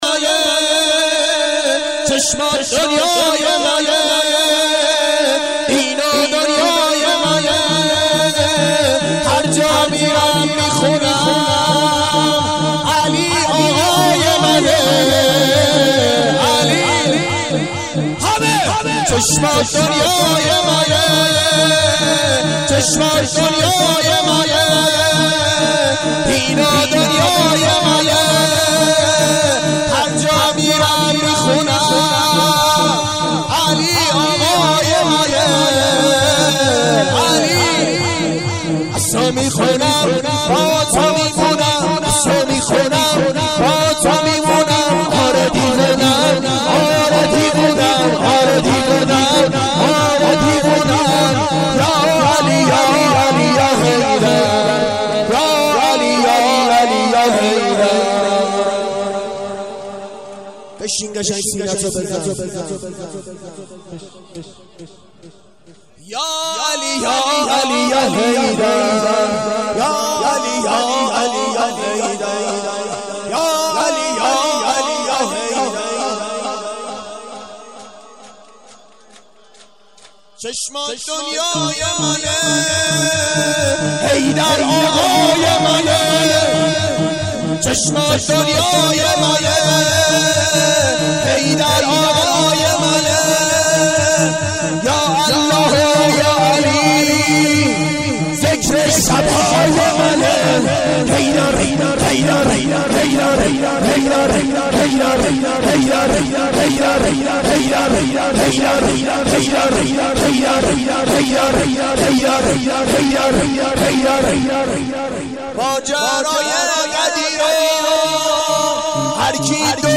شور/چشمات دنیای منه